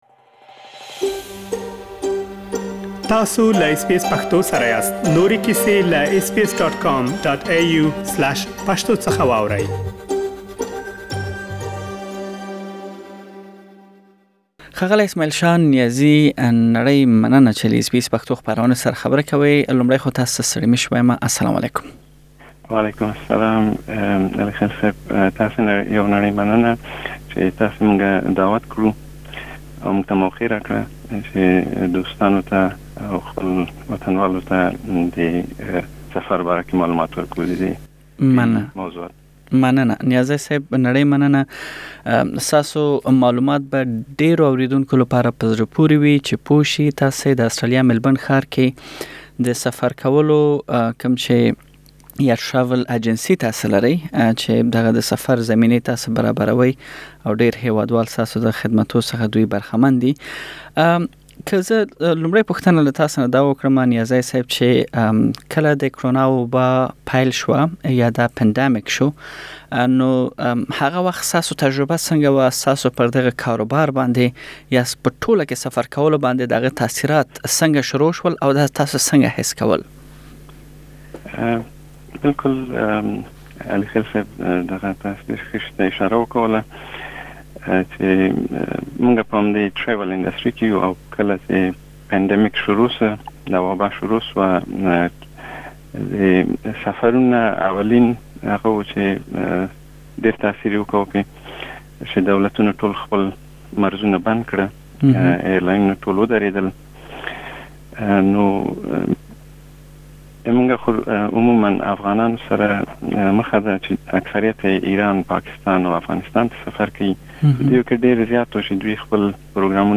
Source: Supplied داچې يادو هيوادونو ته سفر کولو شرايط کوم دي او په اونۍ کې څو ځله الوتنې ترسره کيږي، دا او نور معلومات په مرکه کې واورئ.